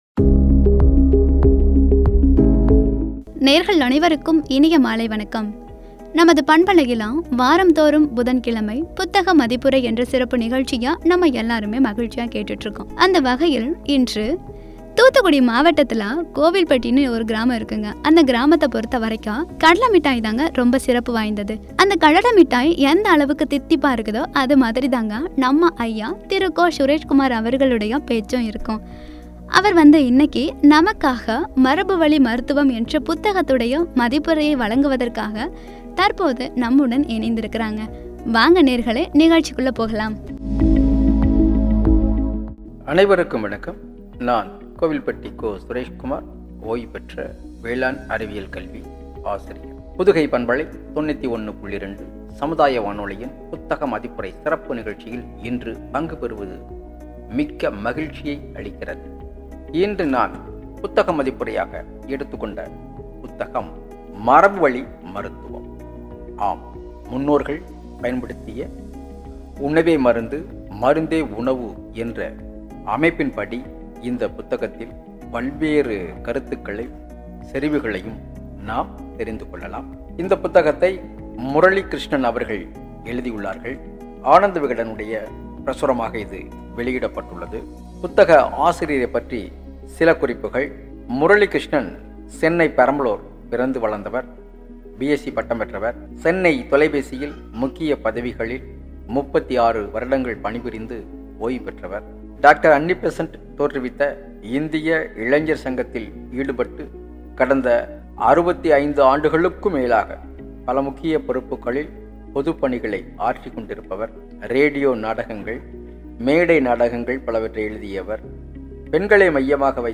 “மரபு வழி மருத்துவம்” (புத்தக மதிப்புரை பகுதி 106)” என்ற தலைப்பில் வழங்கிய உரை.